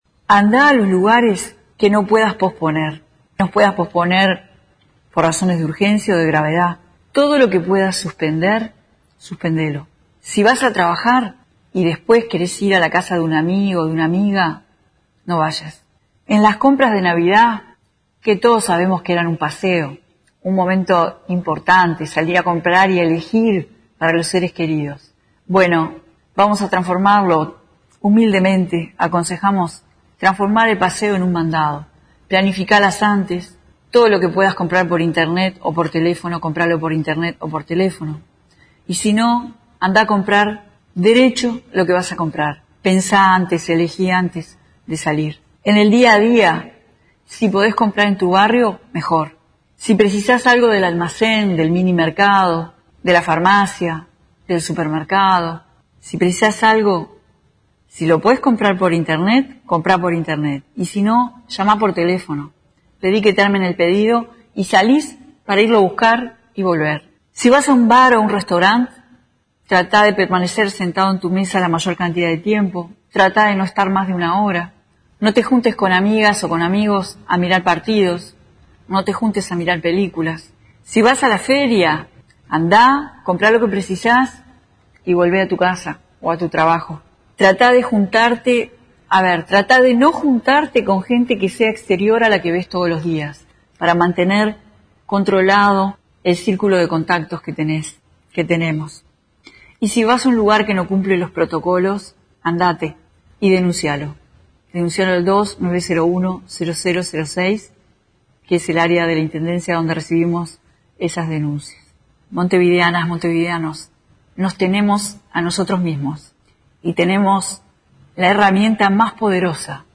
Escuche las declaraciones de la intendenta de Montevideo, Carolina Cosse